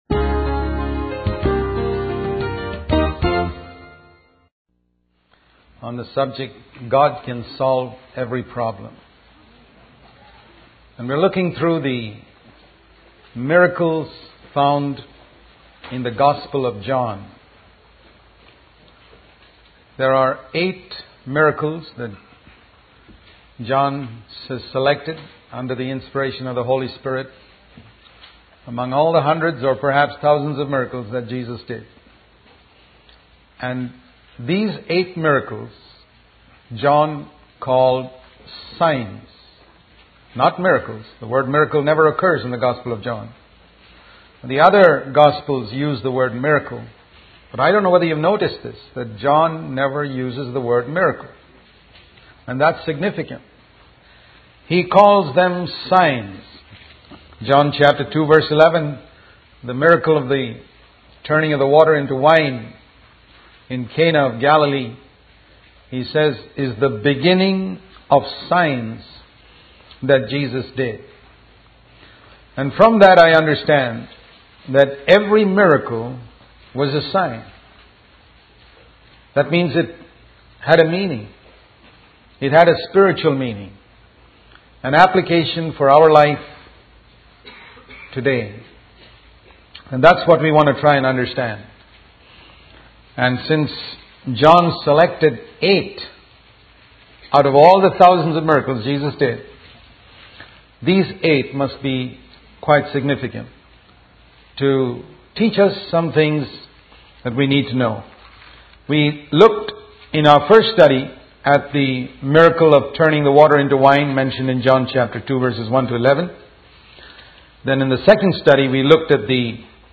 In this sermon, the speaker emphasizes the importance of surrendering one's life to God and partnering with Him in His work. He uses the story of a little boy who became a blessing to thousands by offering his five loaves and two fishes to Jesus.